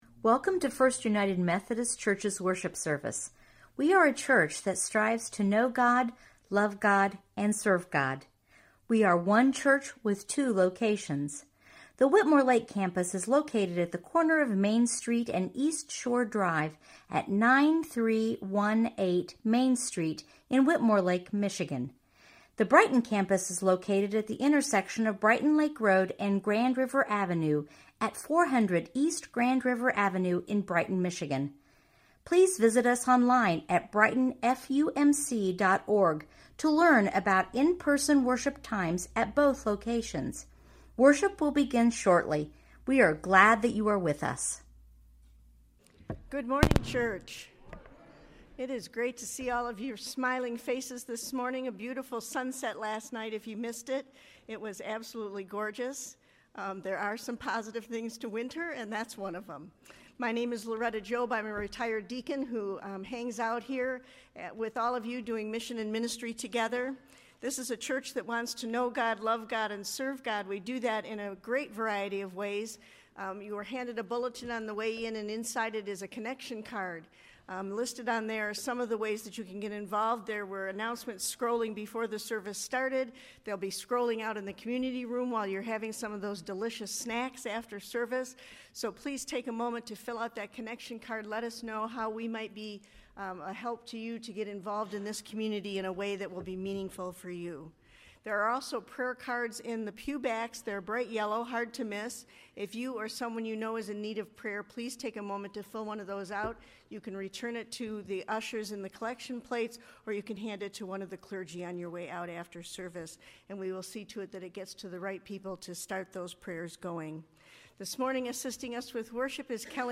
Online Sermons Podcast